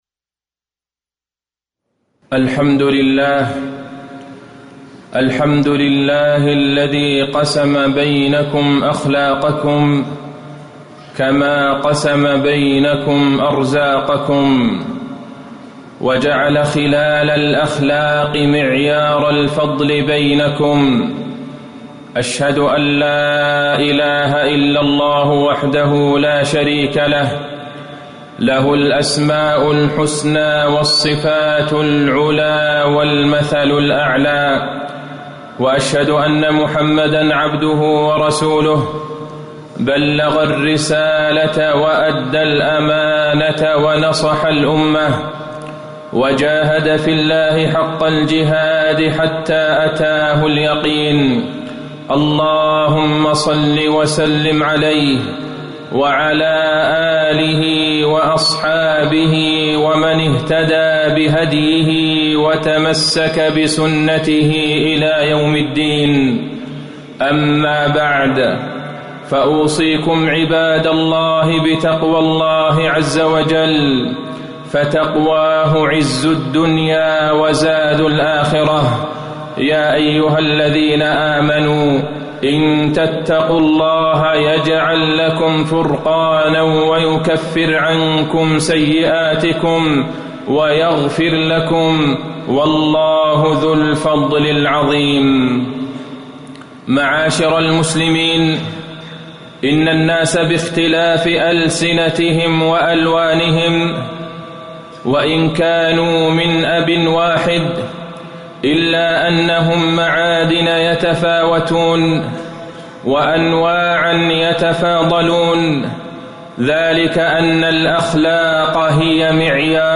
تاريخ النشر ١٩ ذو القعدة ١٤٣٨ هـ المكان: المسجد النبوي الشيخ: فضيلة الشيخ د. عبدالله بن عبدالرحمن البعيجان فضيلة الشيخ د. عبدالله بن عبدالرحمن البعيجان الحياء خير كله